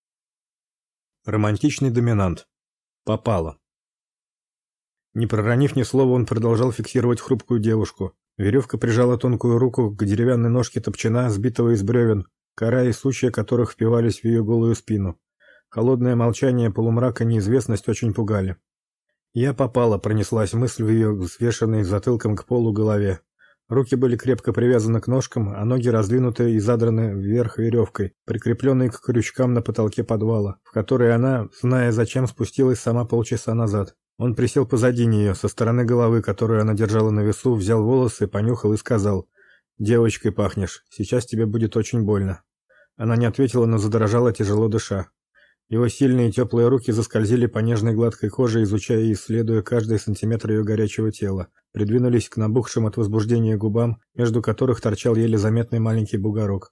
Аудиокнига Попала | Библиотека аудиокниг